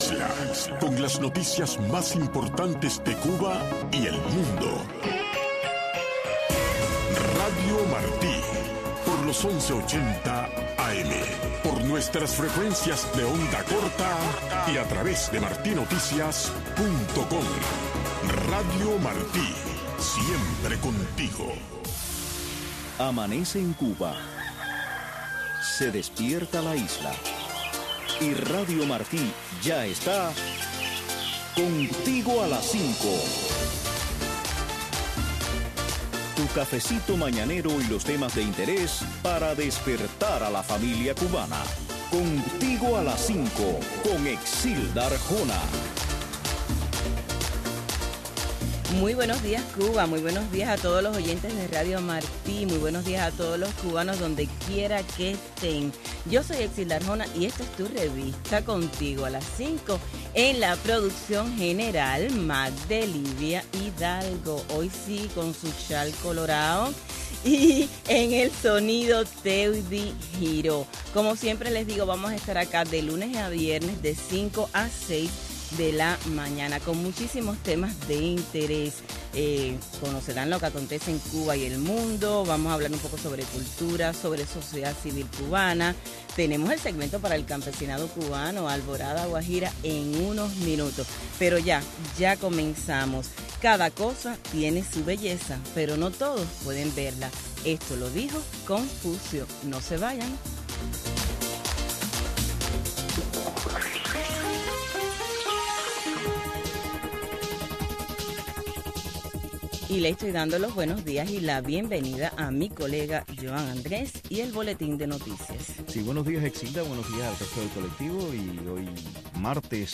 Revista informativa, cultural, noticiosa